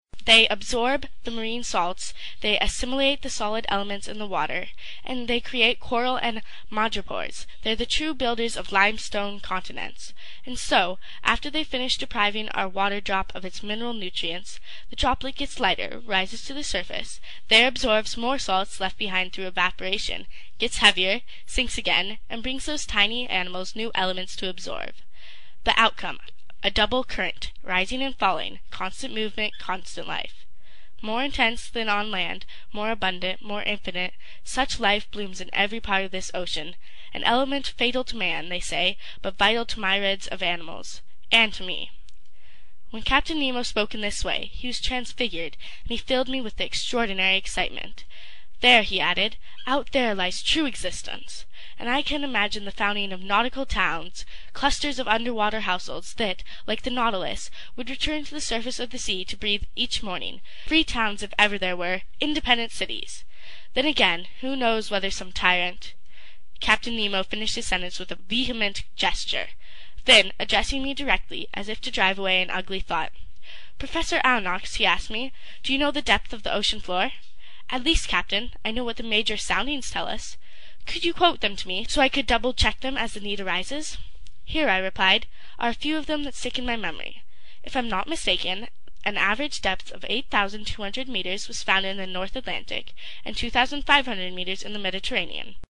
英语听书《海底两万里》第233期 第18章 太平洋下四千里(5) 听力文件下载—在线英语听力室
在线英语听力室英语听书《海底两万里》第233期 第18章 太平洋下四千里(5)的听力文件下载,《海底两万里》中英双语有声读物附MP3下载